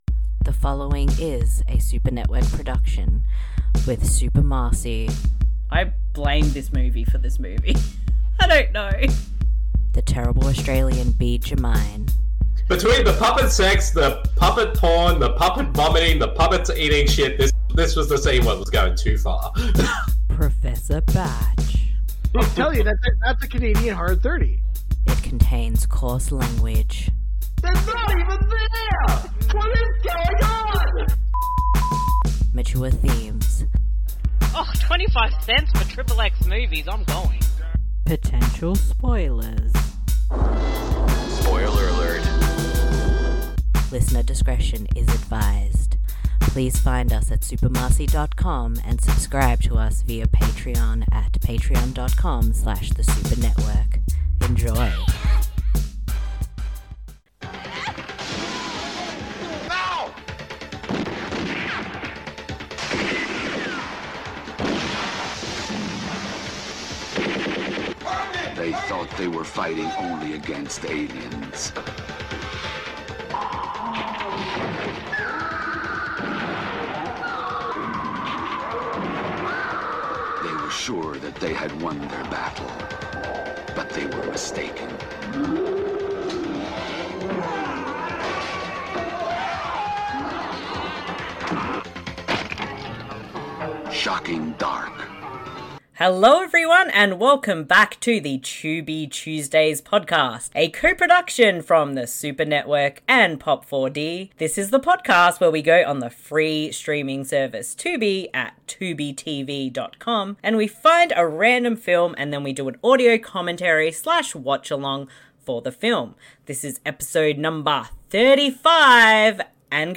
This podcast series is focused on discovering and doing commentaries for films found on the free streaming service Tubi, at TubiTV